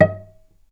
healing-soundscapes/Sound Banks/HSS_OP_Pack/Strings/cello/pizz/vc_pz-D#5-mf.AIF at ae2f2fe41e2fc4dd57af0702df0fa403f34382e7
vc_pz-D#5-mf.AIF